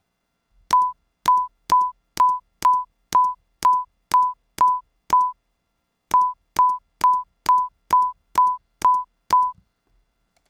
As you can see, the microphone is pointed directly at the keyboard, so as to record the acoustic sound of physically hitting the keys. For this experiment I tried to strike each key quickly and sharply with a fingernail so as to produce the most impulsive possible sound.
Thus, my sound file has the "stimulus" in the left channel and the "response" in the right channel.
I used this trivial Max/MSP patch (which you can download) to produce a 1K sinusoid with an amplitude envelope that goes instantly to full volume at the start of each note and then decays quickly.
Space bar, Core Audio built-in sound, iovs = sigvs = 64, Overdrive on, SIAI on,